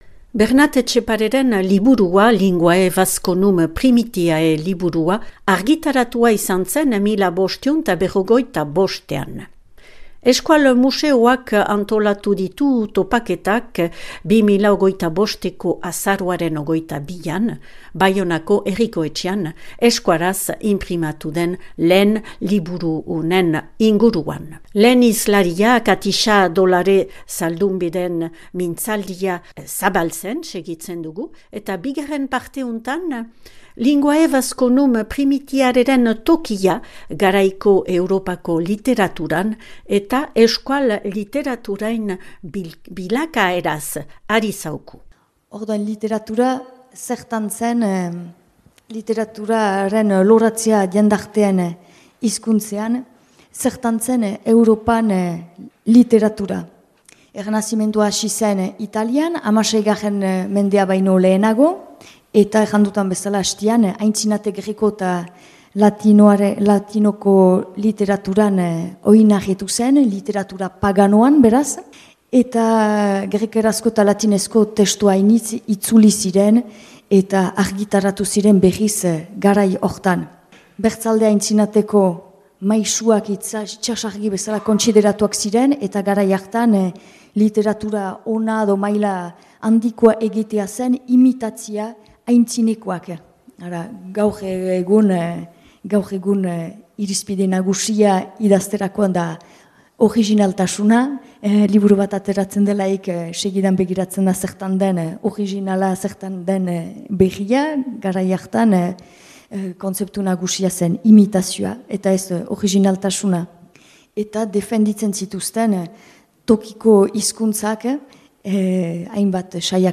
Euskaraz inprimatu lehen liburuaren inguruko topaketak, Euskal museoak antolaturik 2025eko azaroaren 22an Baionako Herriko Etxean.